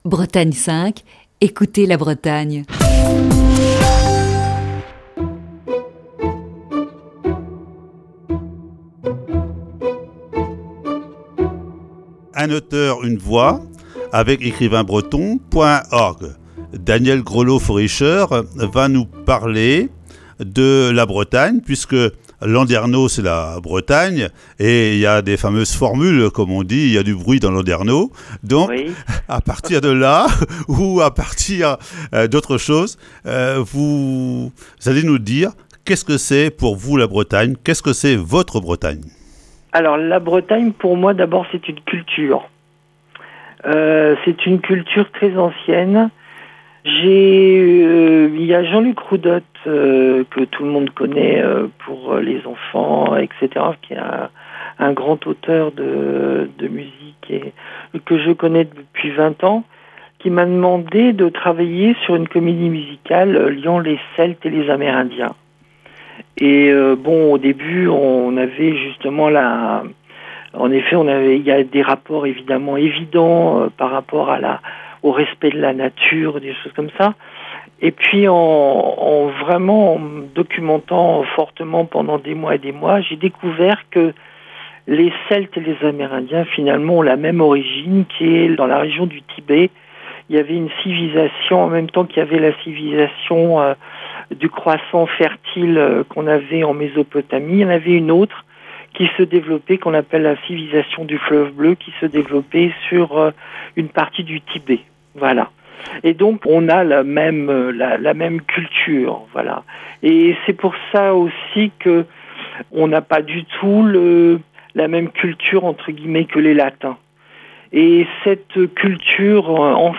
Voici ce mardi la deuxième partie de cette série d'entretiens.